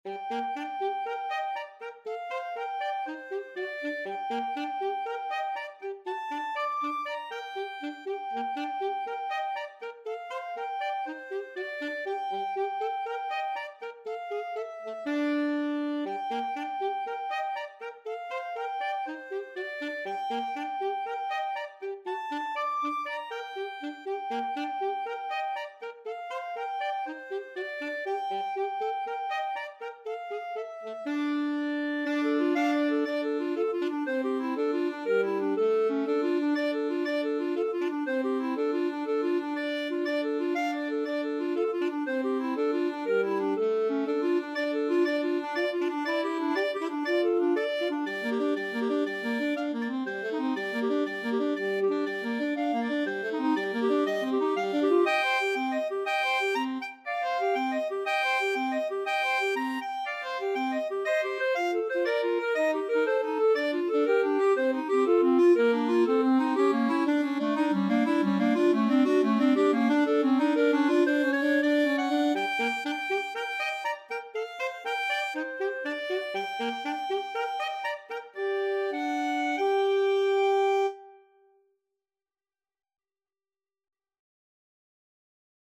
ClarinetAlto Saxophone
2/2 (View more 2/2 Music)
Allegro (View more music marked Allegro)